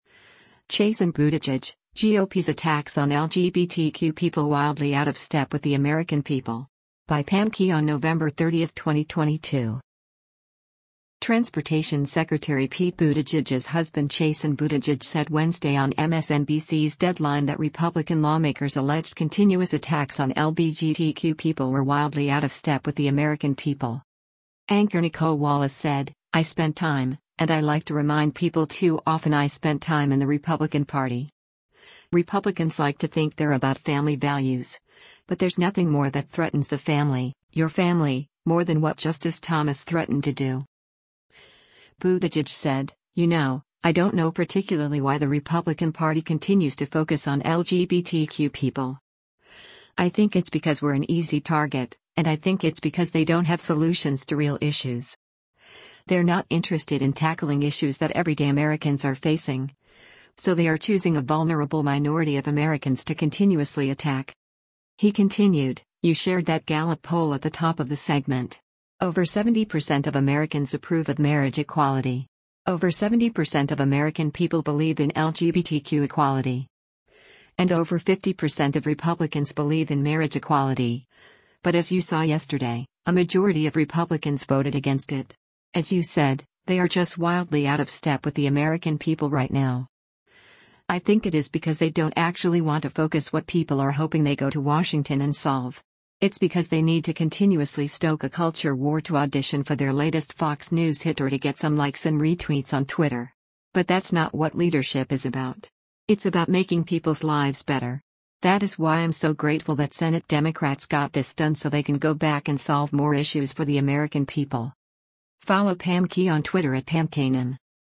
Transportation Secretary Pete Buttigieg’s husband Chasten Buttigieg said Wednesday on MSNBC’s “Deadline” that Republican lawmakers’ alleged continuous attacks on LBGTQ people were “wildly out of step with the American people.”